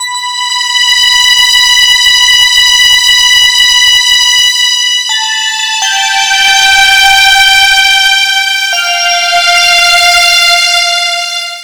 01 Captains Logg B 165bpm.wav